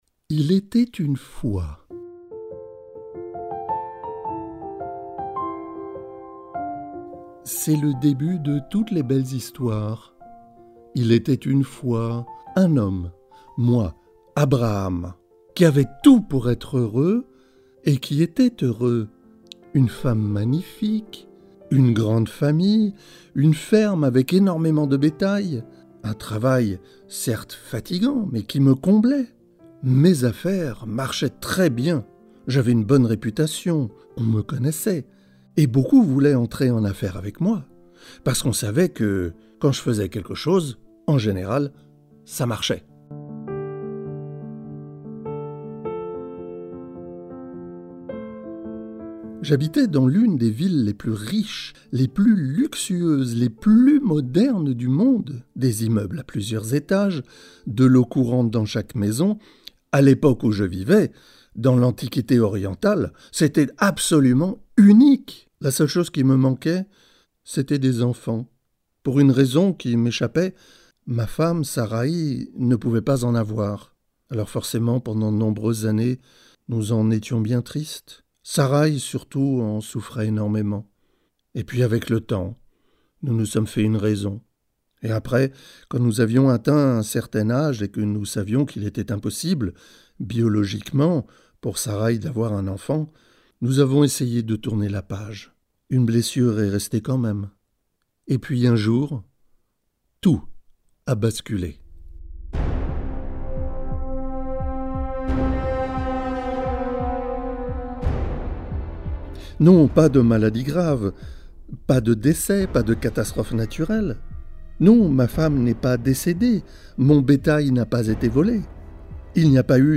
Message biblique